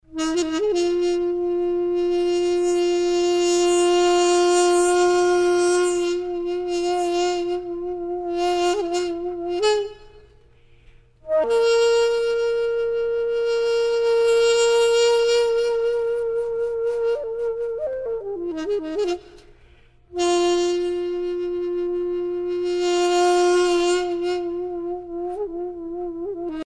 Angryongsan (musique de cour)
flûte traversière taegum